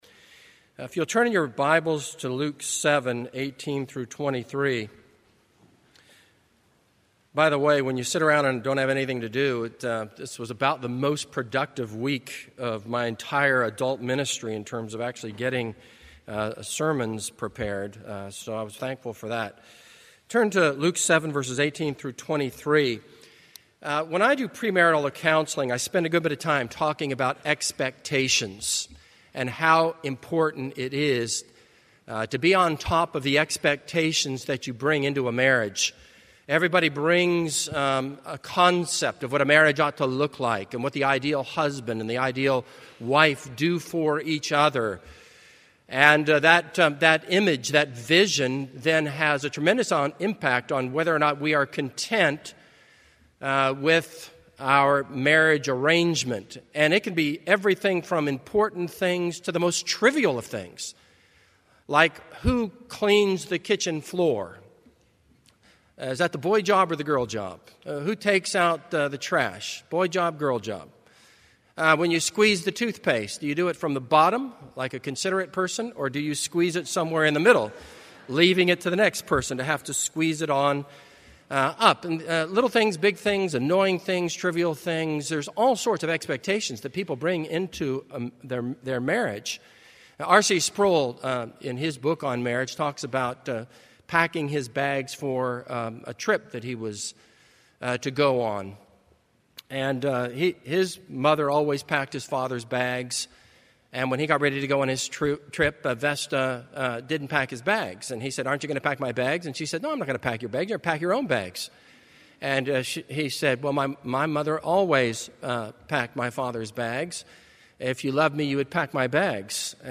This is a sermon on Luke 7:18-23.